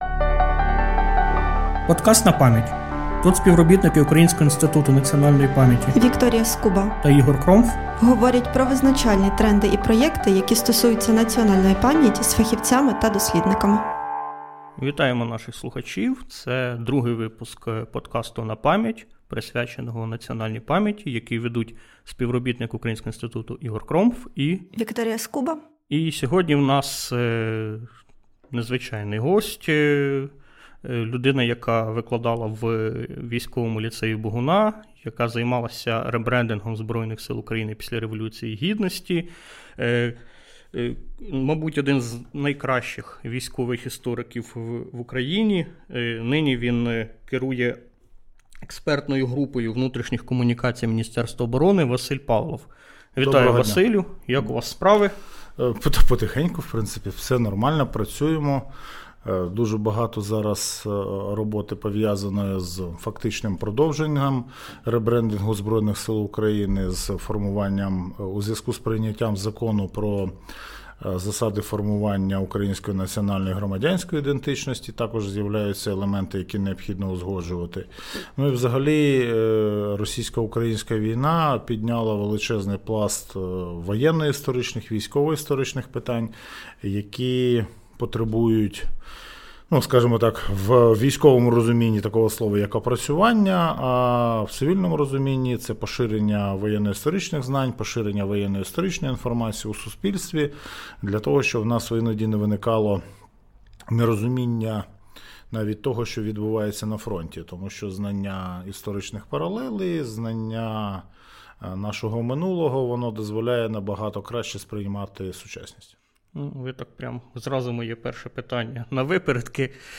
Розмова